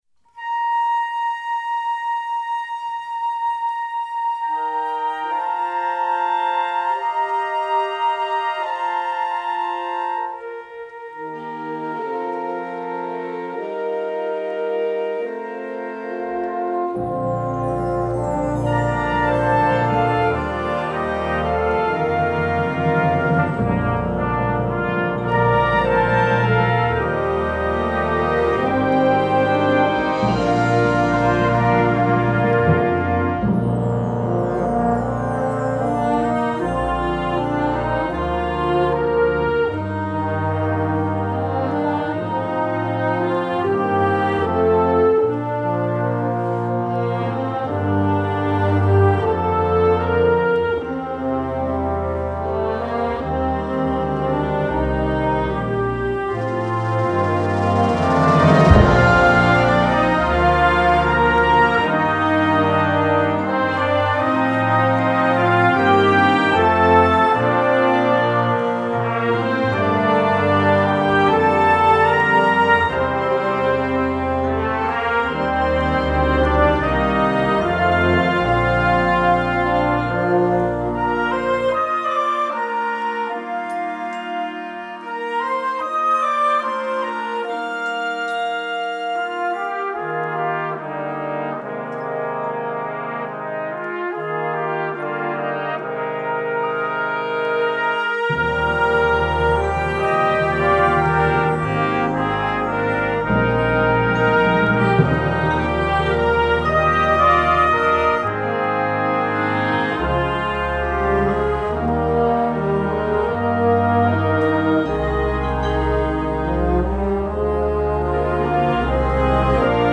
4:15 Minuten Besetzung: Blasorchester Tonprobe